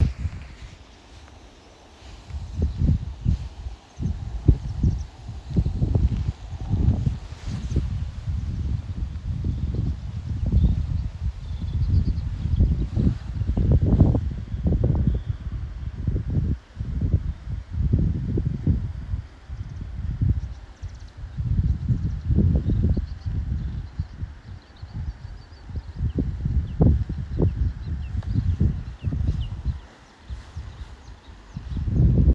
болотная камышевка, Acrocephalus palustris
Administratīvā teritorijaCarnikavas novads
СтатусПоёт